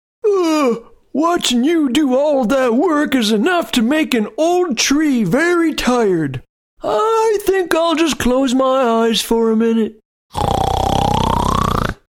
Project: Voices and music for online reading program